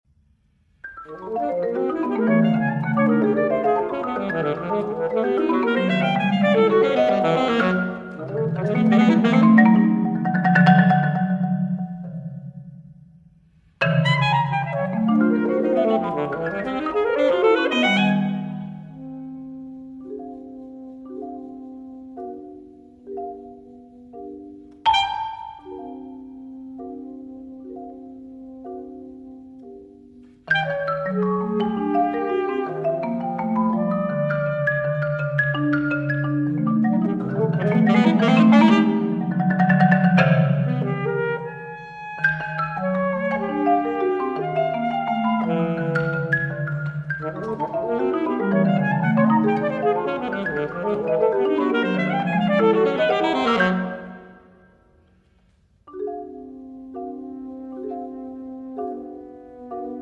saxophone
marimba